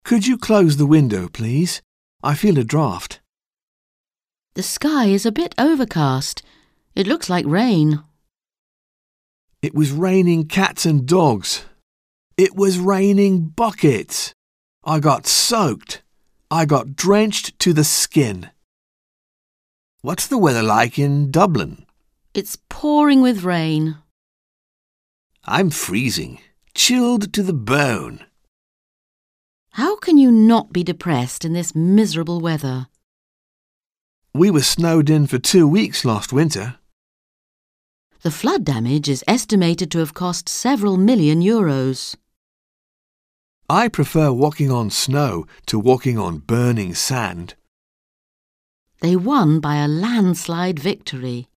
Un peu de conversation - La pluie, le vent et le froid